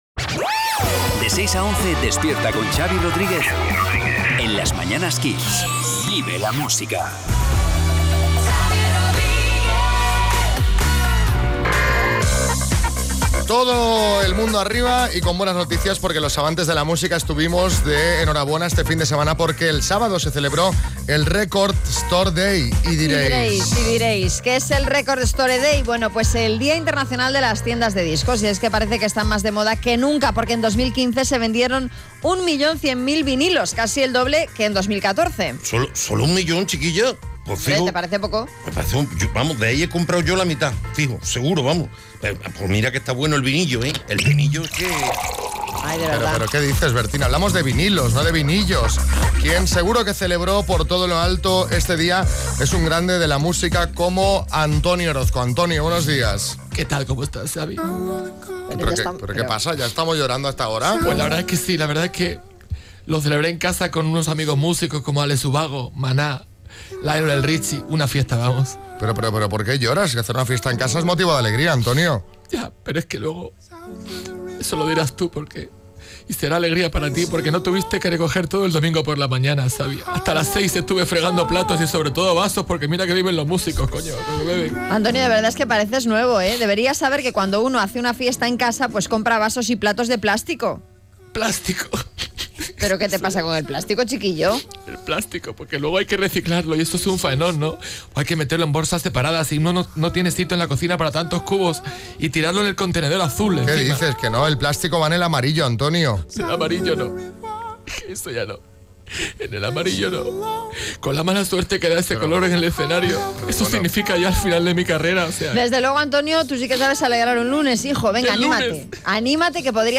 Nuestro Antonio Orozco, más triste de lo normal, nos ha visitado para comentar la noticia.